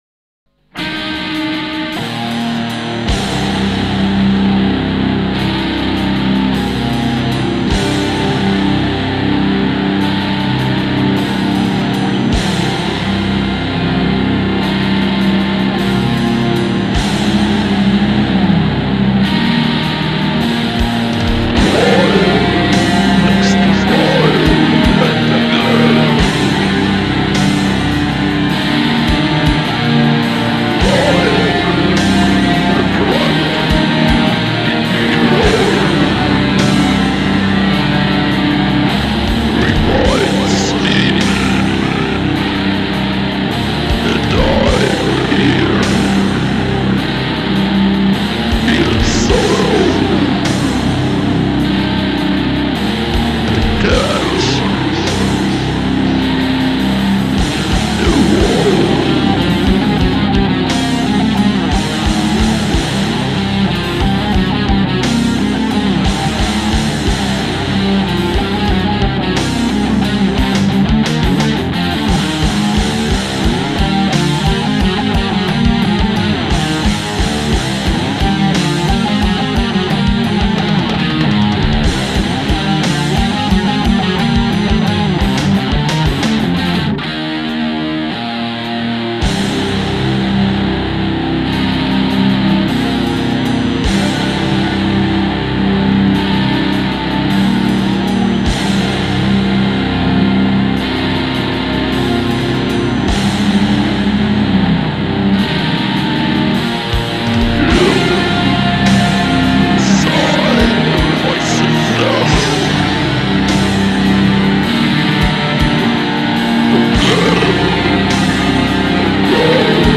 w warunkach domowych.